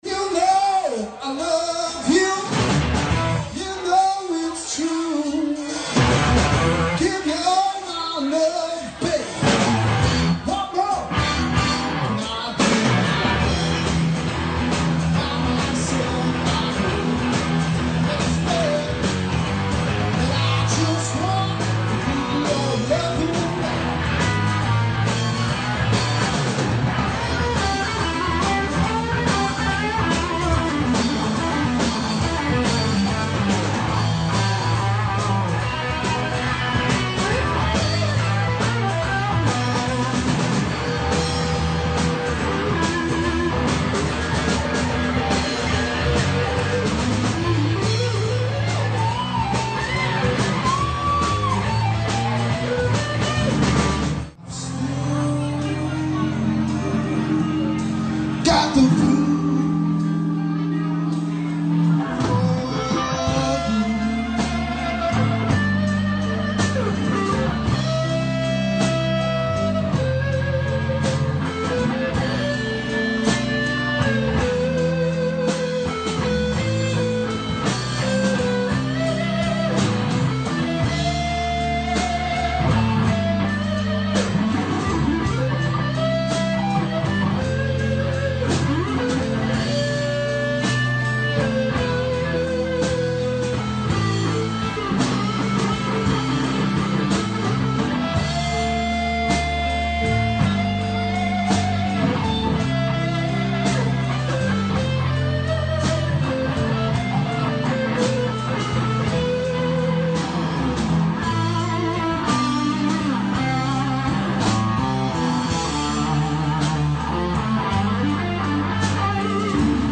(Live)
lead guitar